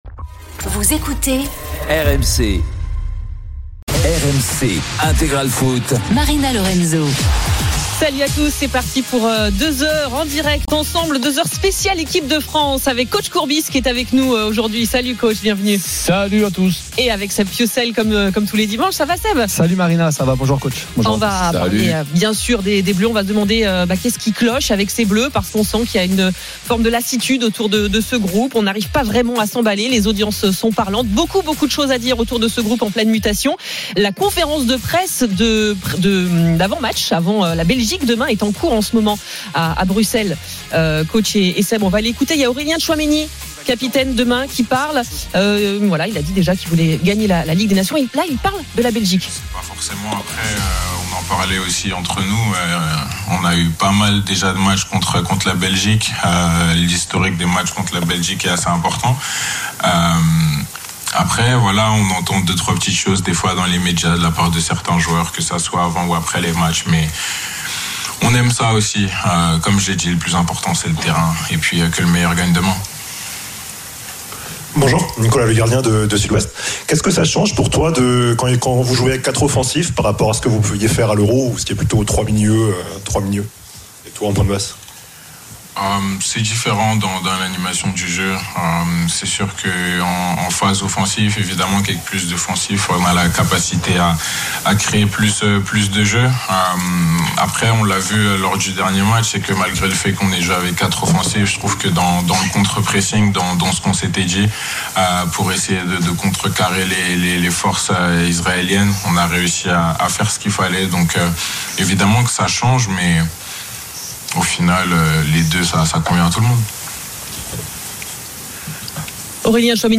Rugby, natation, Formule 1… tous les sports et grands événements sont à suivre en live sur RMC,